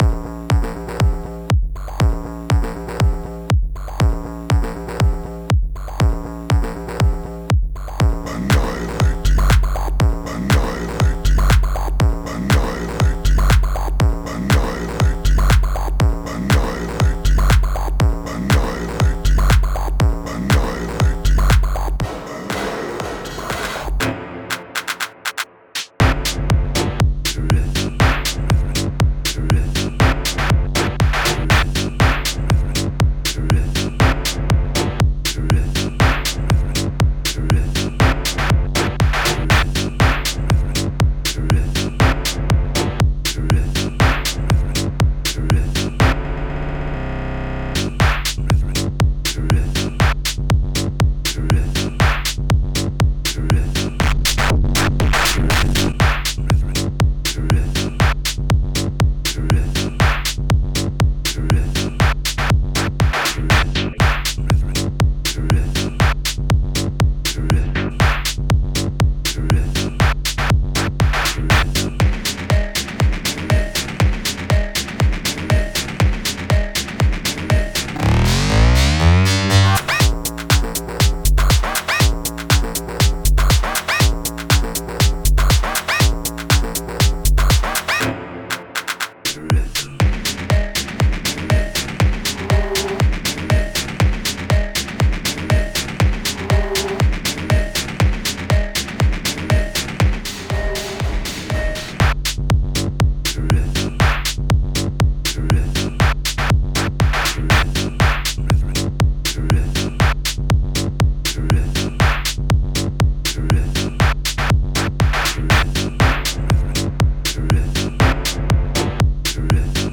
Fired up koala this evening, it’s been a while…such a good self contained workflow :smiley::raised_hands:t2:… did this little recording